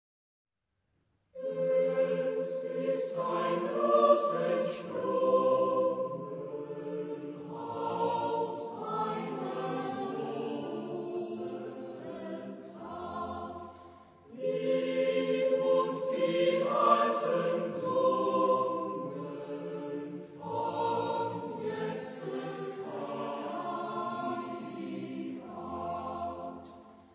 Genre-Stil-Form: Liedsatz ; Volkslied ; Choral
Chorgattung: SATB  (4 gemischter Chor Stimmen )
Tonart(en): F-Dur
Knabenchor